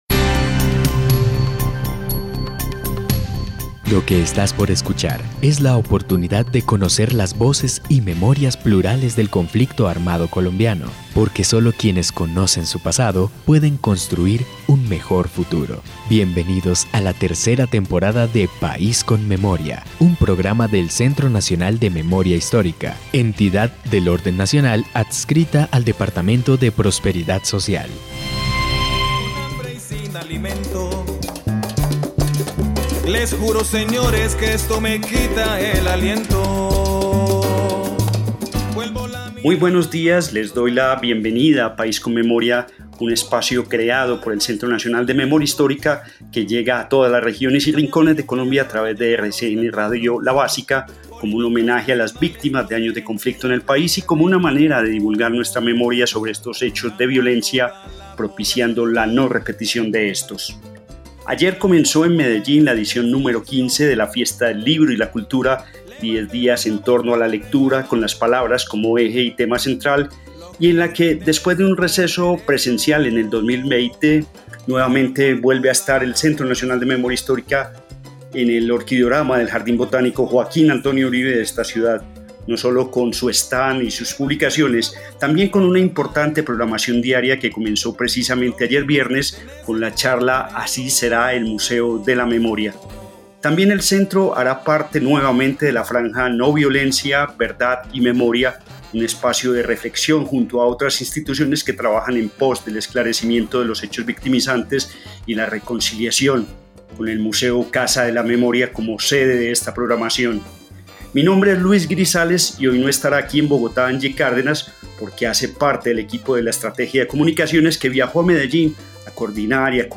Descripción (dcterms:description) Capítulo número 24 de la tercera temporada de la serie radial "País con Memoria". Charla sobre la edición no.15 de la Fiesta del Libro y La Cultura celebrada en Medellín.